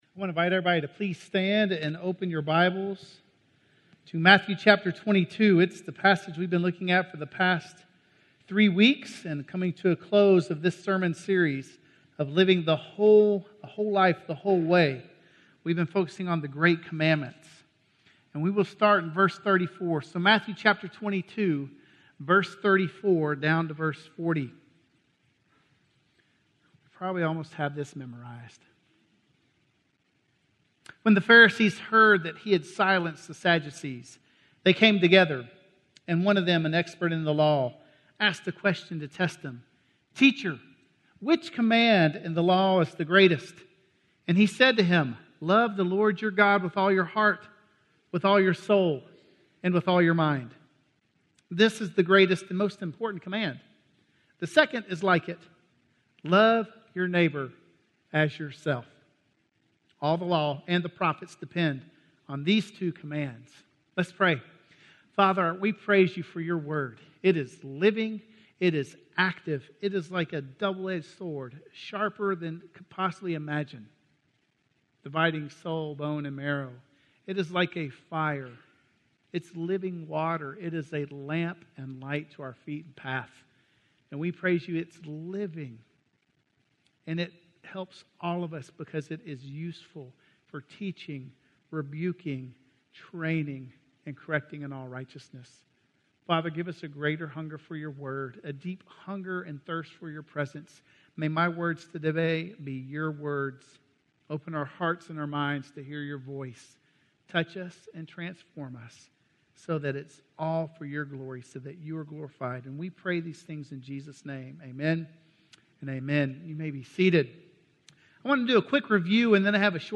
Loving God With All Your Mind - Sermon - Woodbine